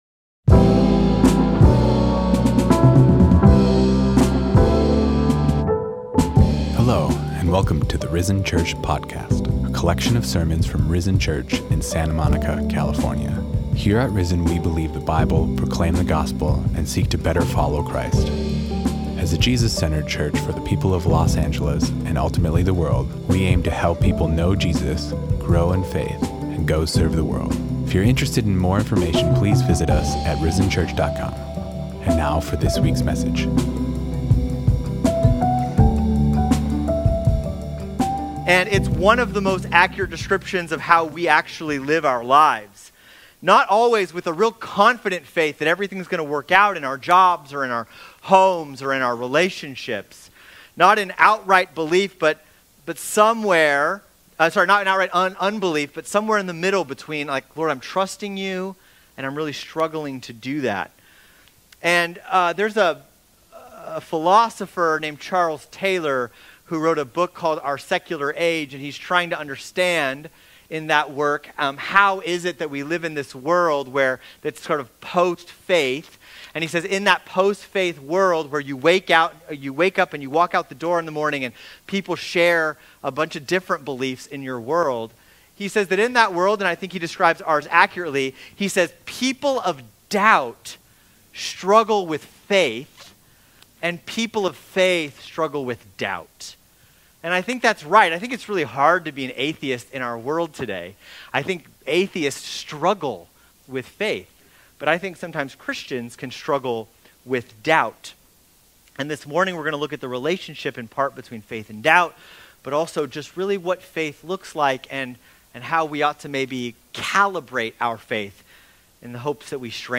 Sermons | Risen Church Santa Monica